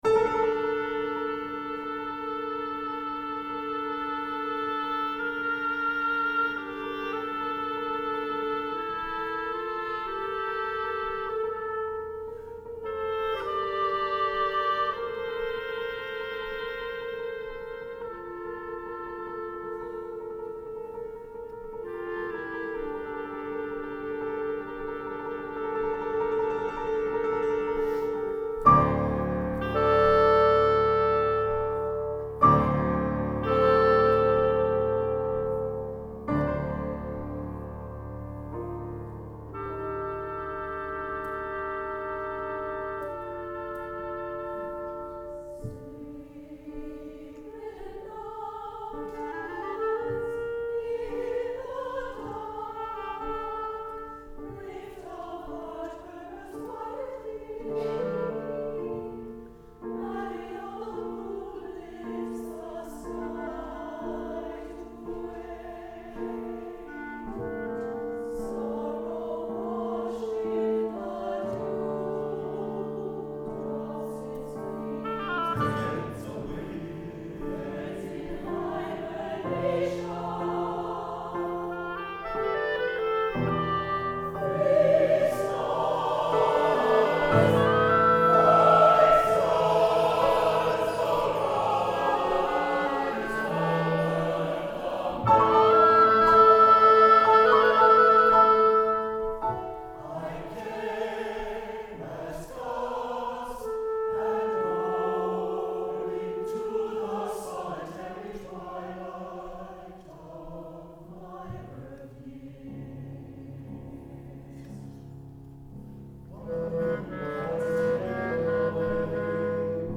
for soprano, baritone, chorus, and instruments